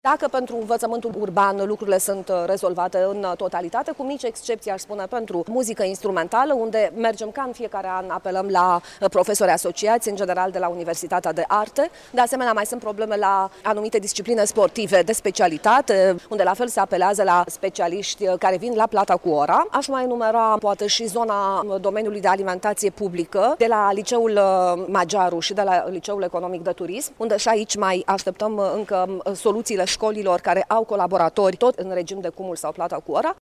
Șeful Inspectoratului Școlar Iași, Camelia Gavrilă, a declarat  că există licee în municipiul reședință de județ unde sunt posturi rămase libere, însă acestea vor fi ocupate de cadre  didactice ce vor fi plătite pentru numărul de ore susținute: